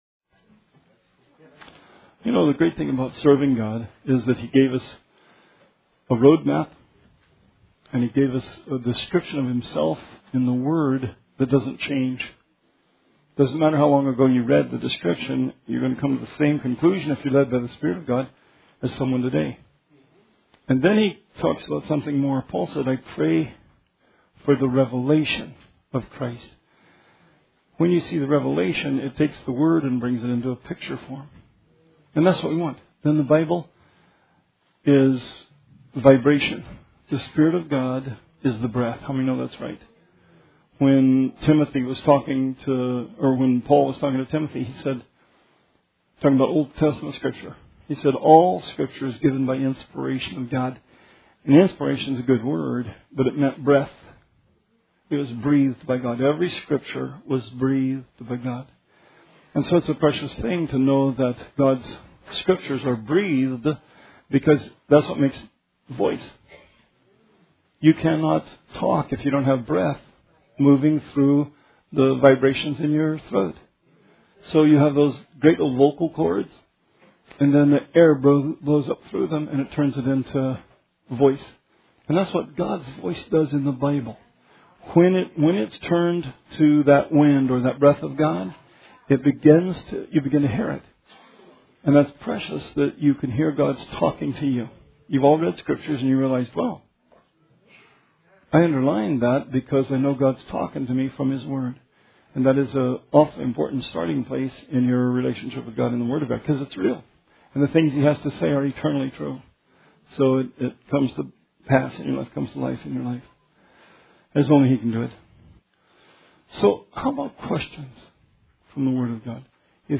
Bible Study 5/10/17